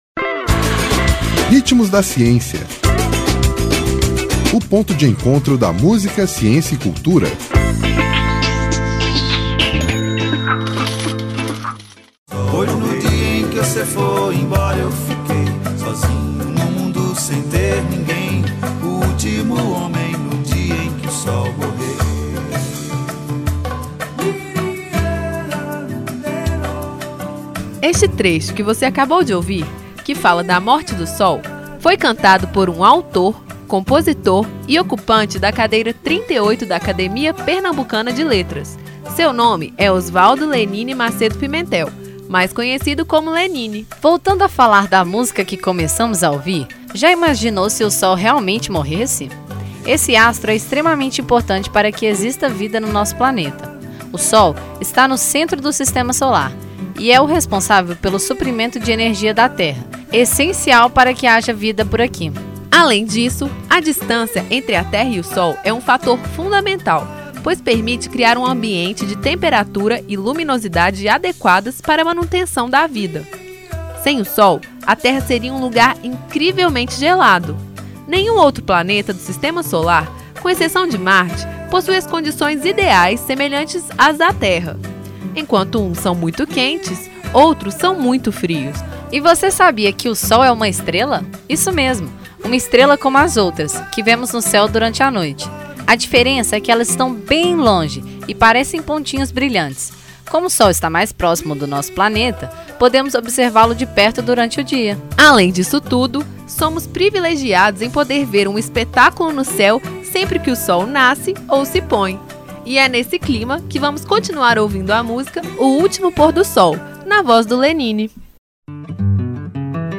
Este trecho que você acabou de ouvir, que fala da morte do sol, foi cantado por um autor, compositor e ocupante da cadeira 38 da Academia Pernambucana de Letras.
Intérprete: Lenine